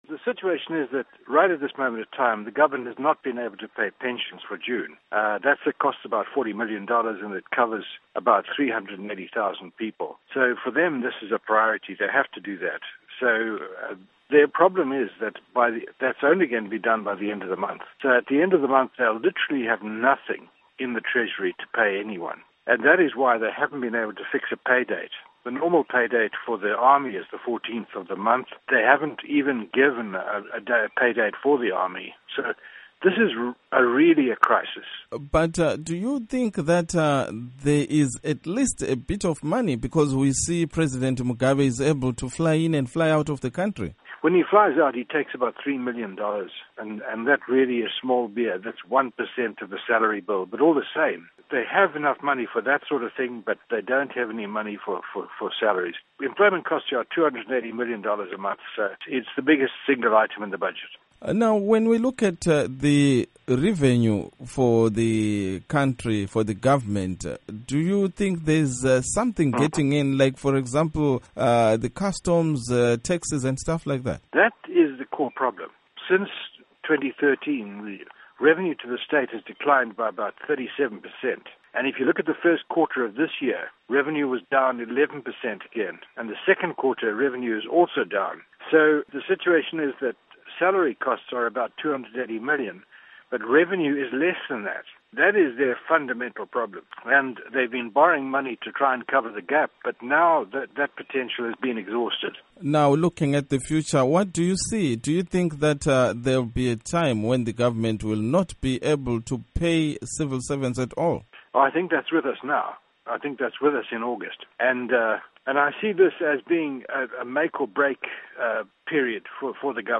Interview With Eddie Cross